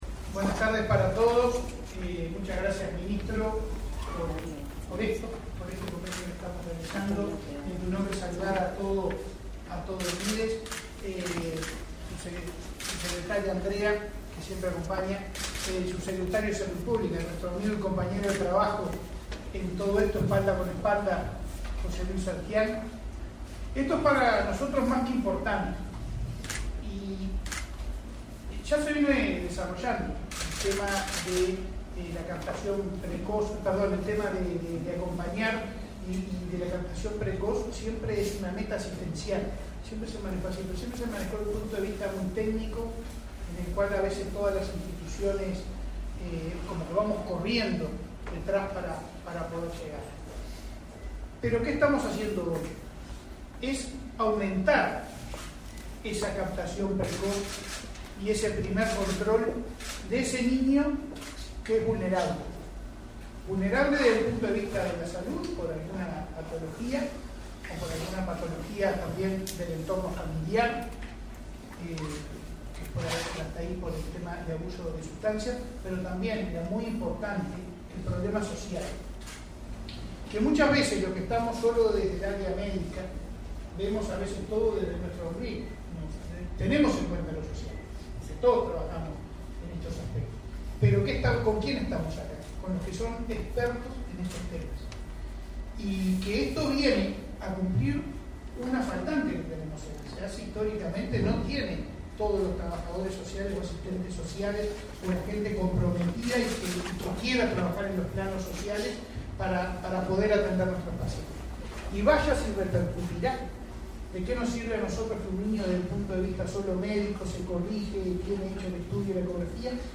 Conferencia de prensa por firma de acuerdo entre Mides y ASSE
Conferencia de prensa por firma de acuerdo entre Mides y ASSE 11/10/2022 Compartir Facebook X Copiar enlace WhatsApp LinkedIn El Ministerio de Desarrollo Social (Mides) y la Administración de los Servicios de Salud del Estado (ASSE) suscribieron, este 11 de octubre, un acuerdo para fortalecer la atención de embarazadas y la primera infancia, mediante un diagnóstico social precoz de los recién nacidos. Participaron el titular del Mides, Martín Lema, y el presidente de ASSE, Leonardo Cipriani.